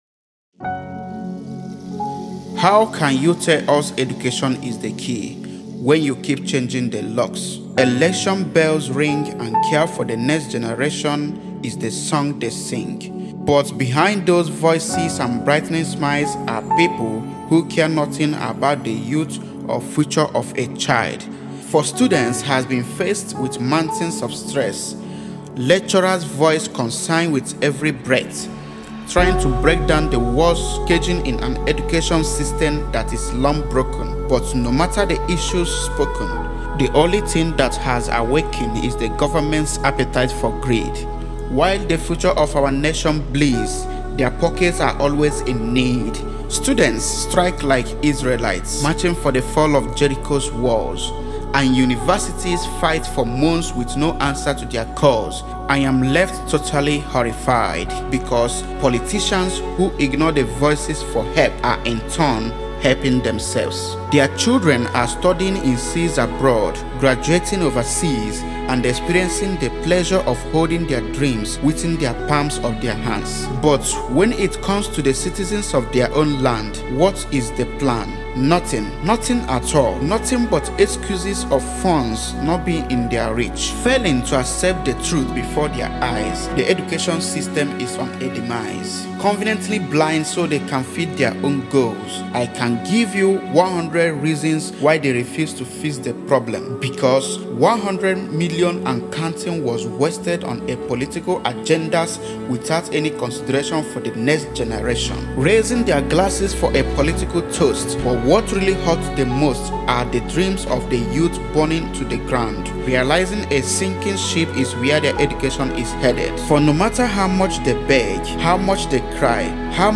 A compelling and penetrating look into the depths of an ailing educational system is provided by the poignant spoken word analysis of a system long overdue for change.